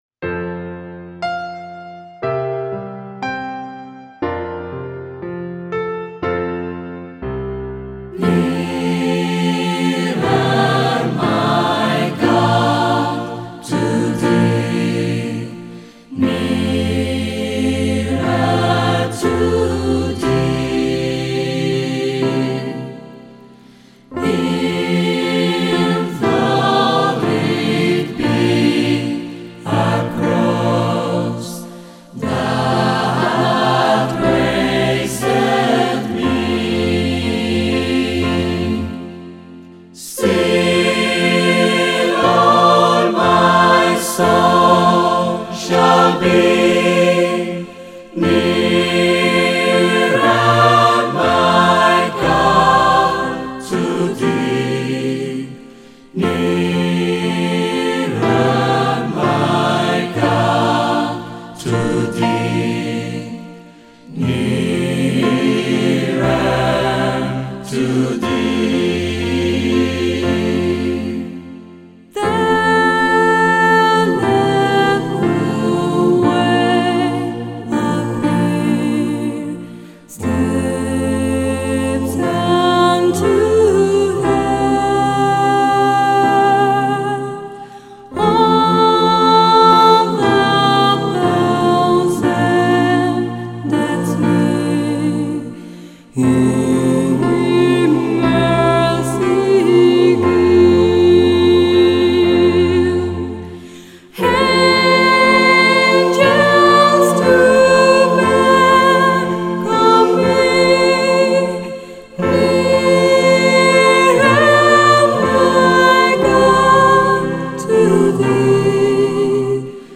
Dependiendo del estilo y del tema concreto, cantamos a capella o con instrumentación de acompañamiento opcional (teclado).